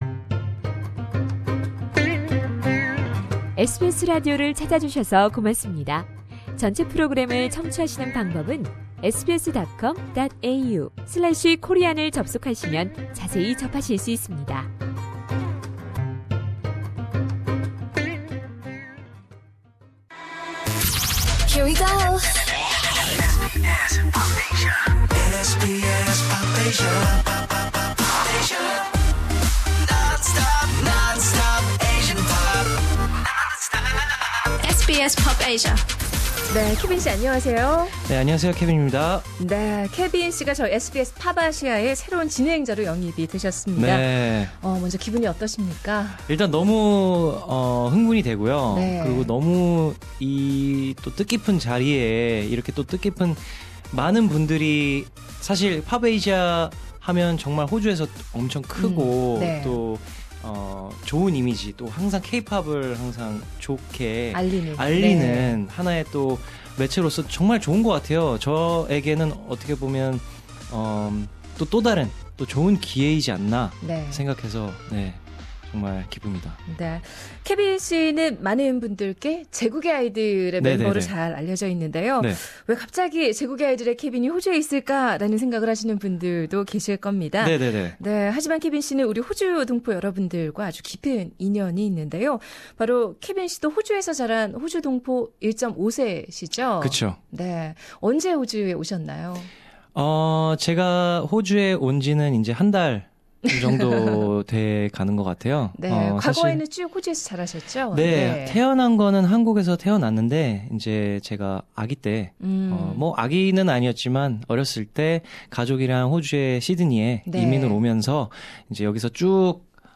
상단의 다시 듣기(Podcast)를 통해 케빈 김과의 인터뷰를 만나보세요.